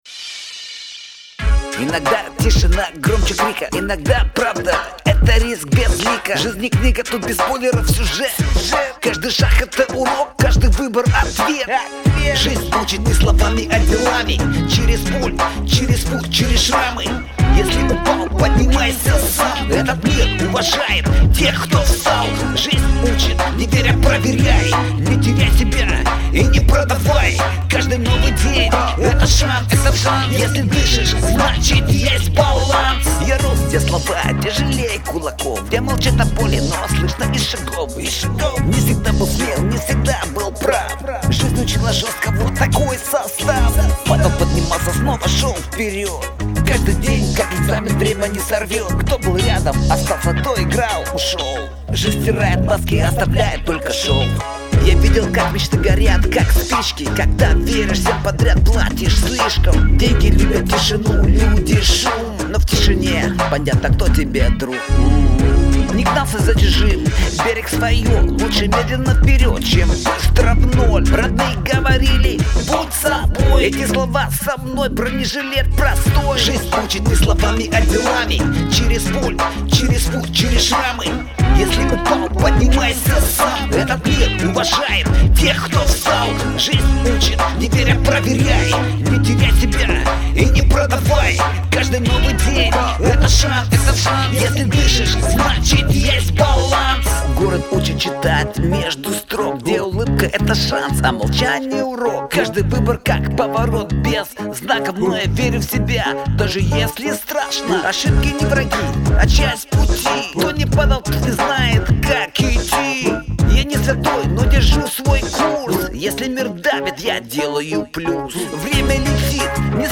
• Жанр: Хип-хоп
• Жанр: Рэп